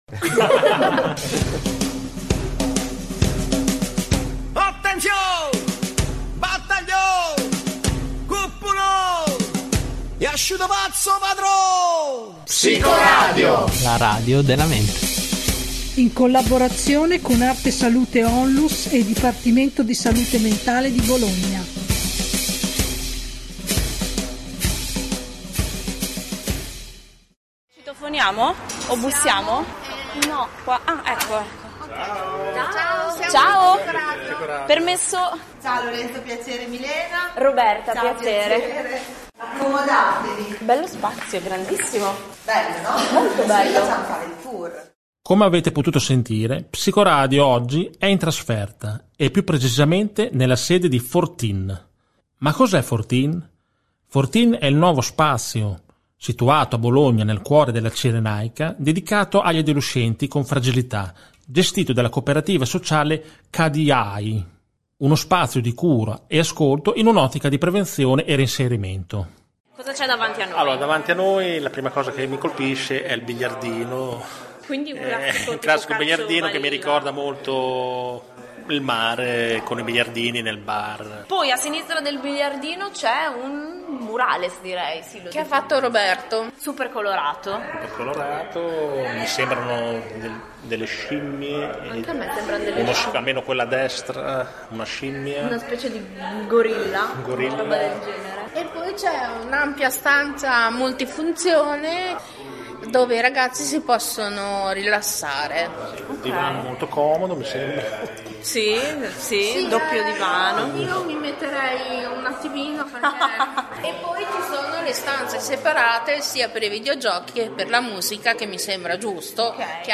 Psicoradio è andata a trovare Forteen, uno spazio situato a Bologna, nel cuore della Cirenaica, dedicato agli adolescenti con fragilità gestito dalla Cooperativa sociale CADIAI. Uno spazio di cura e ascolto, in un’ottica di prevenzione e reinserimento.